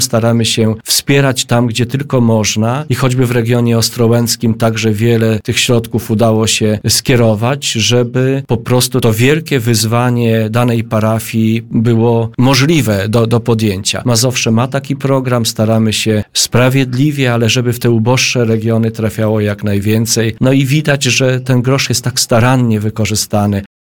Na antenie Radia Nadzieja radny sejmiku województwa Janusz Kotowski, który zasiada w Komisji Kultury i Dziedzictwa Narodowego, przypomniał, że podobne programy w województwie mazowieckim z powodzeniem realizowane są od lat.